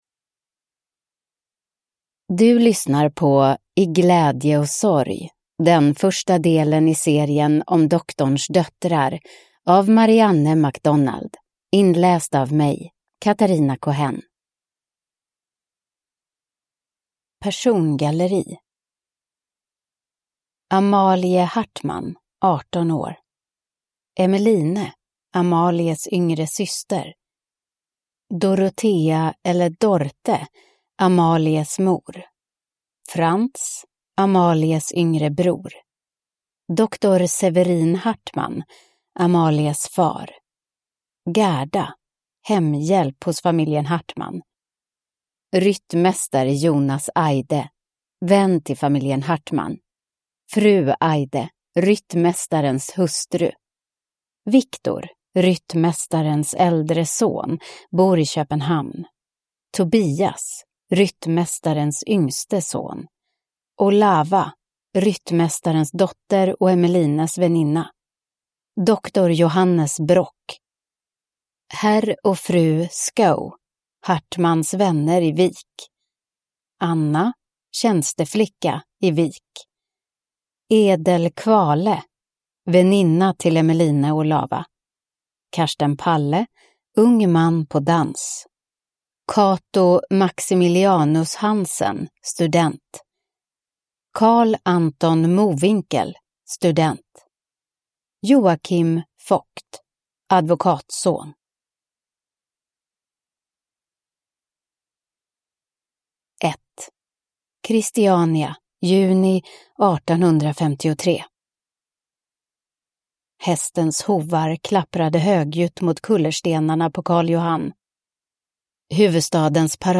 I glädje och sorg (ljudbok) av Marianne MacDonald